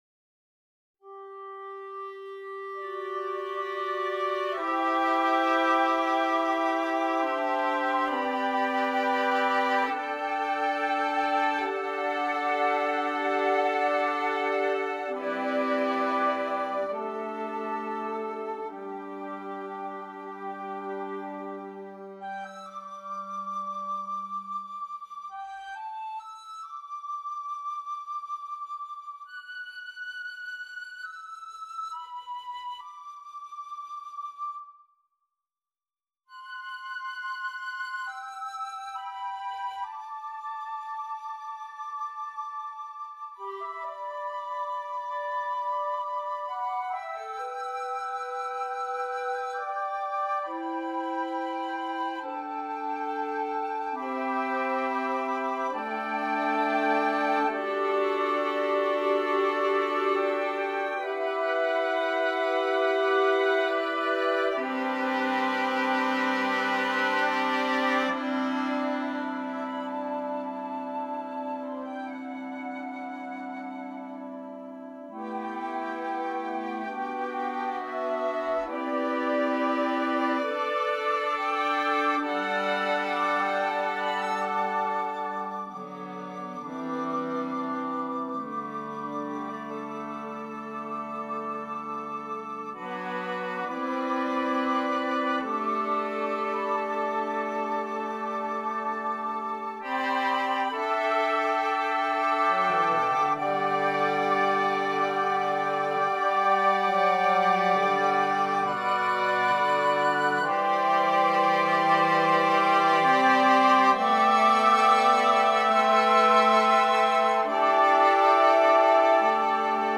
2 Flutes, 2 Oboes, 2 Clarinets, Bass Clarinet, Bassoon